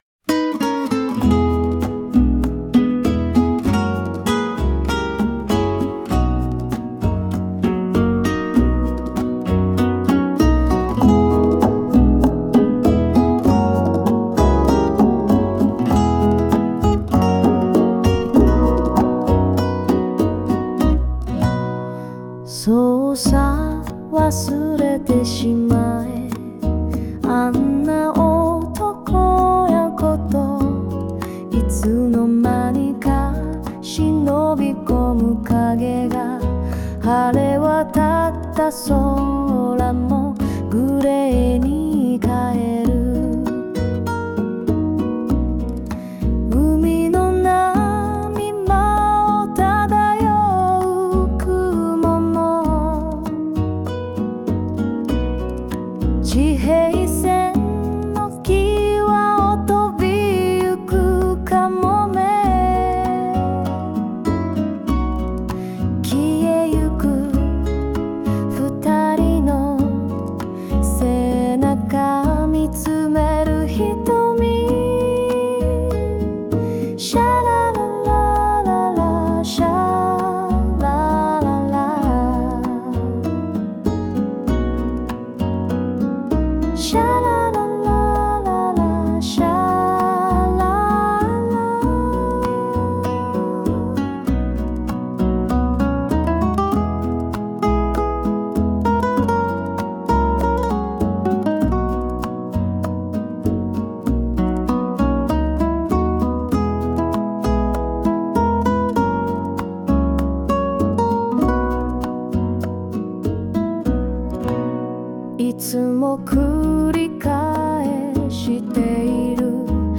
＊この楽曲は有料版SNOW AI を使って創作しました。